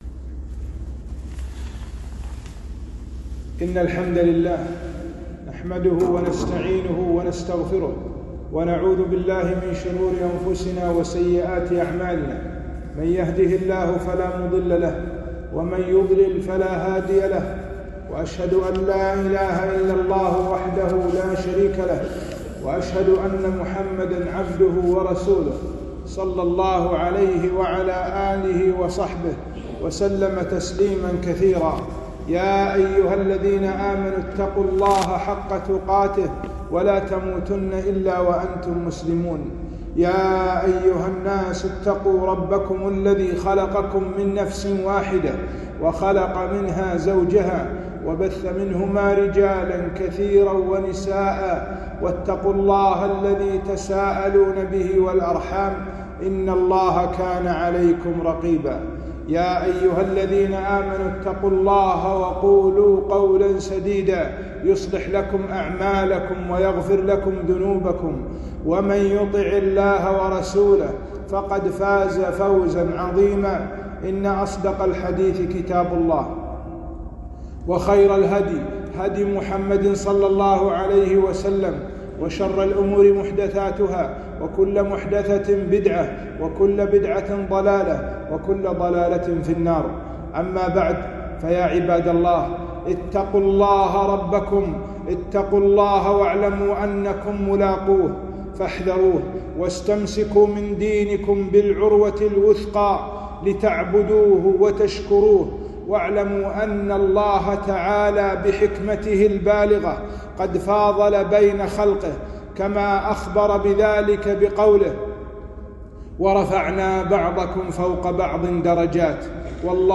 خطبة - مكانة الصحابة في الأمة